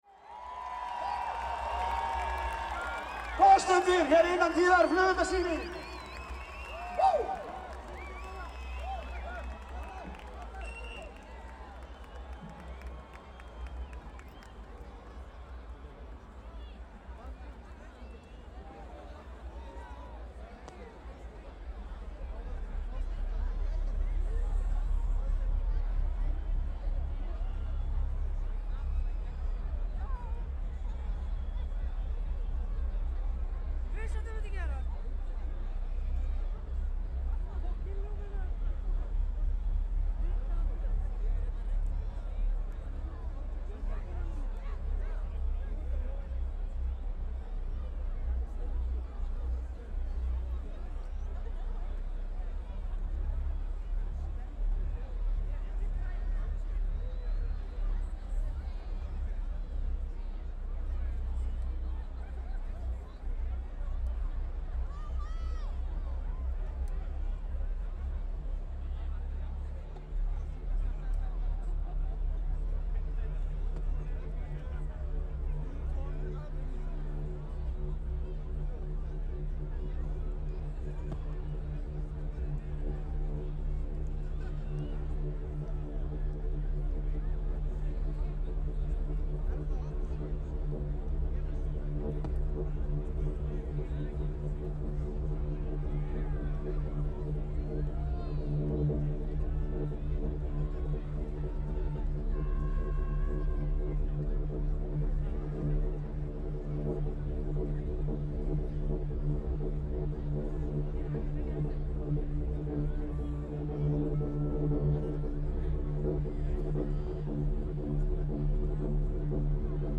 This year the weather was wet but warm. I was soaked when the fireworks started, and I was probably not in the best place to record fireworks. But it was as interesting to listen to the joyful crowd during the show. In just 9 minutes they blew up three tons of fireworks from several places around the concert stage.
Því miður má heyra það á upptökunni að vindhlífin var, þegar þarna kom við sögu, orðin gegnblaut.
Sound Devices 744 Mics: Sennheiser MKH8040 (ORTF) Pix: Nokia N82.